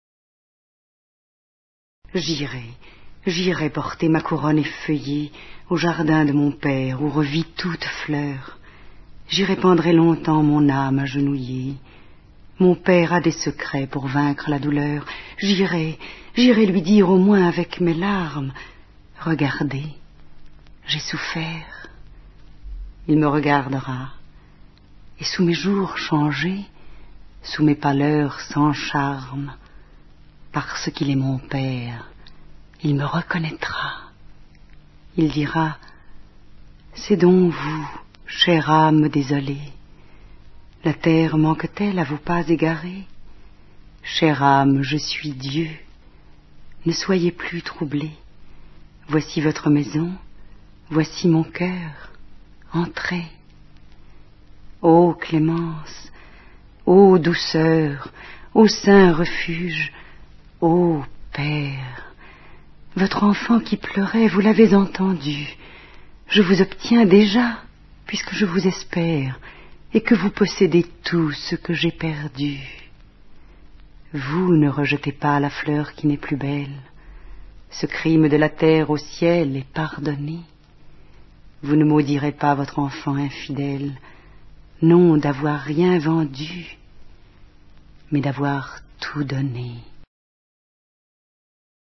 dit par Maria MAUBAN